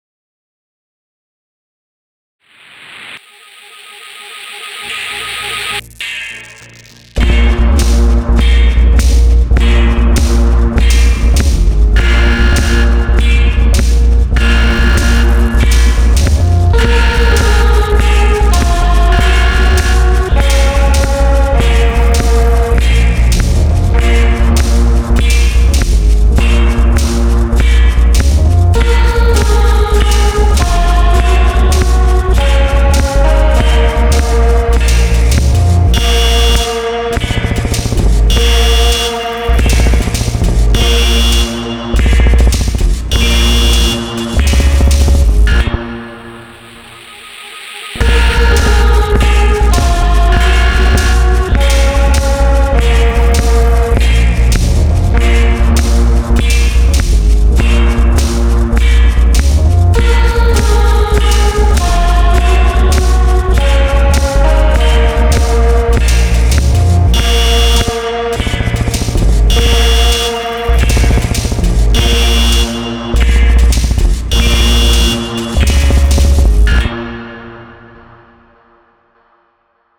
This large collection of 400 presets have been crafted using the included bespoke sample content and Kontakt internal waveforms, which draws on Mechanical, Electrical & Synthetic sound sources.
The sample sources include various consumer electronics, DIY power tools, stone & masonry, and larger industrial machinery, as well as a complementary collection of synth, granular, loops and processed samples.
Here is a quick demo track, put together using only sounds from Industry: